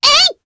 Selene-Vox_Attack2_jp.wav